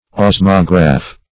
Search Result for " osmograph" : The Collaborative International Dictionary of English v.0.48: osmograph \os"mo*graph\ ([o^]z"m[-o]*gr[.a]f), n. [Osmose + -graph.] (Physics) An instrument for recording the height of the liquid in an endosmometer or for registering osmotic pressures.